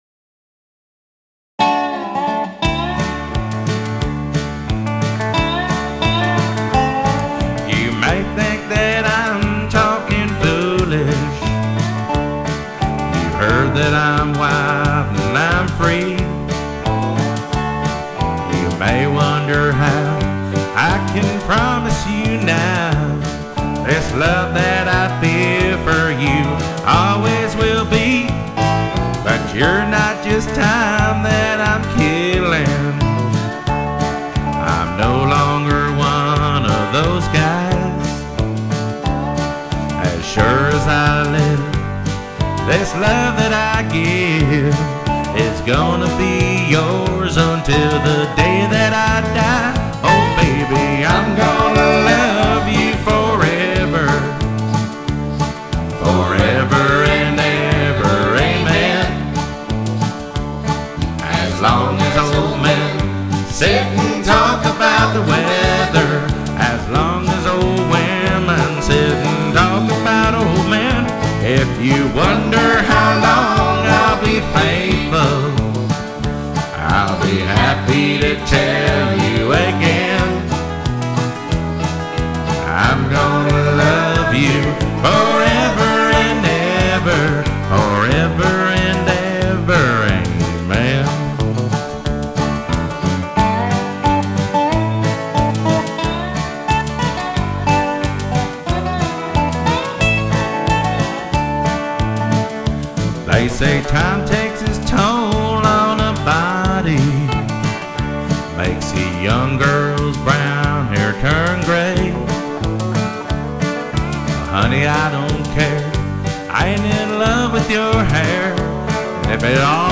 Karaoke Songs